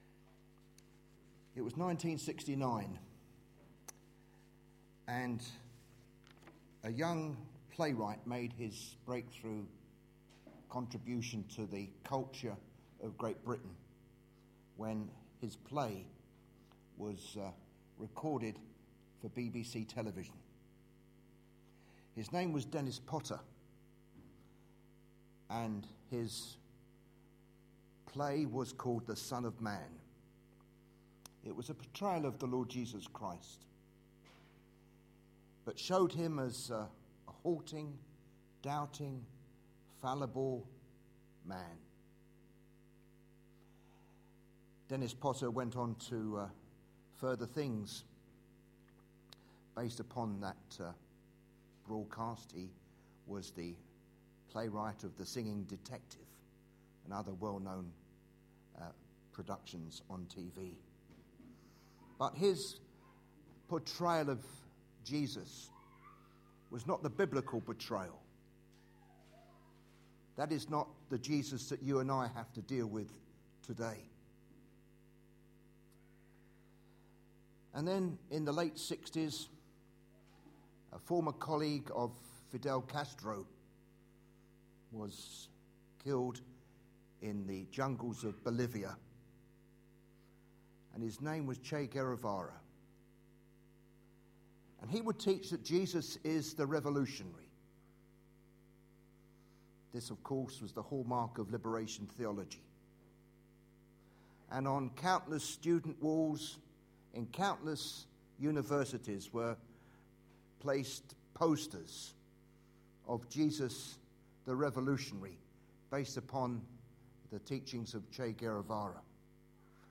A sermon preached on 11th March, 2012, as part of our Mark series.